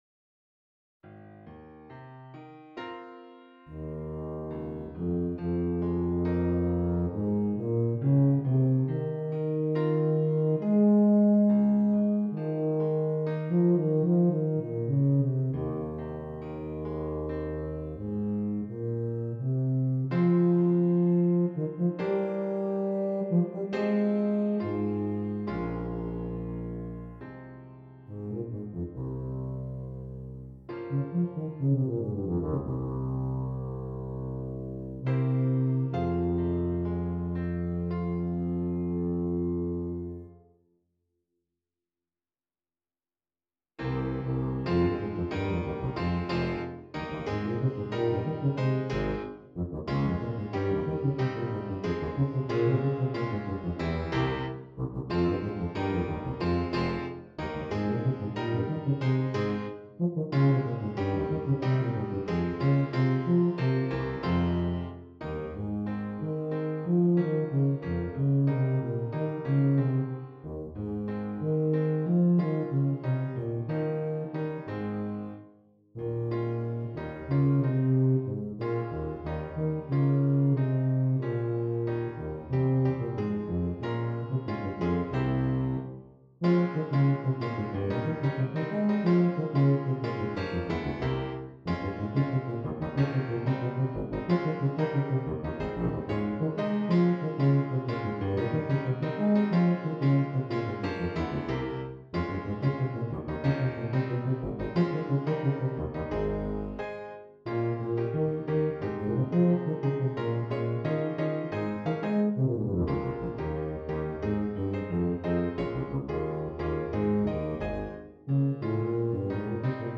Tuba and Keyboard